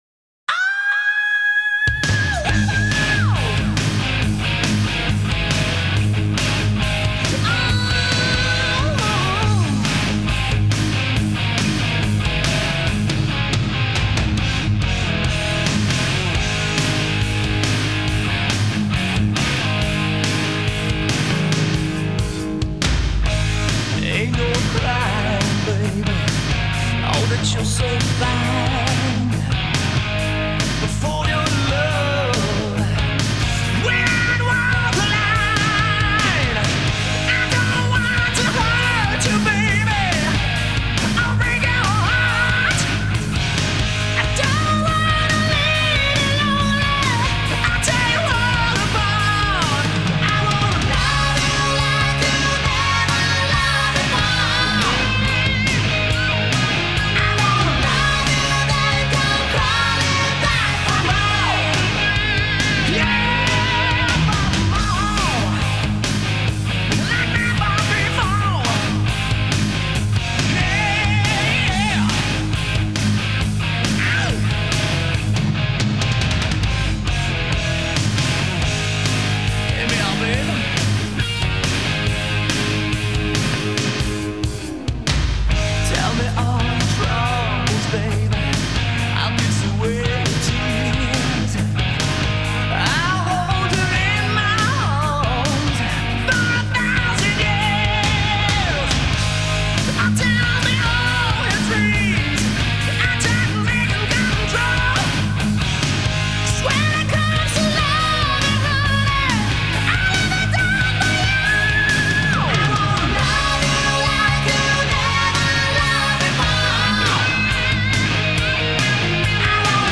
американская глэм-метал-группа